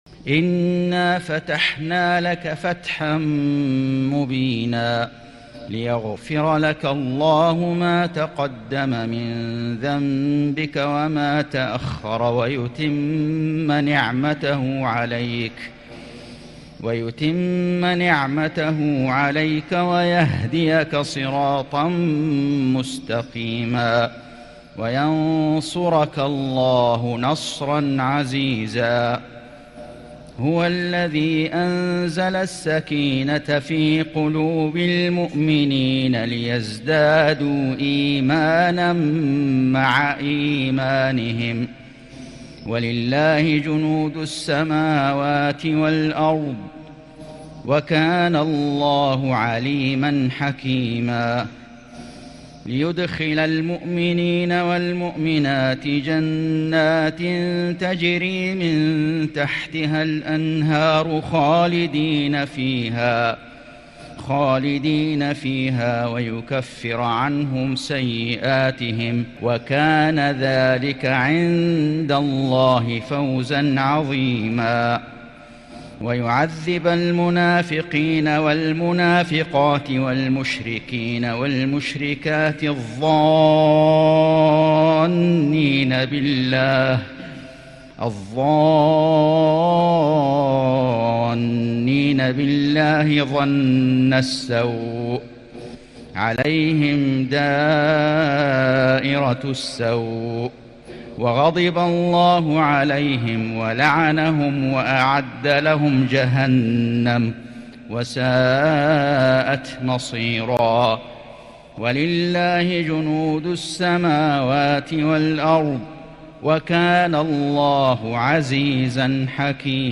سورة الفتح > السور المكتملة للشيخ فيصل غزاوي من الحرم المكي 🕋 > السور المكتملة 🕋 > المزيد - تلاوات الحرمين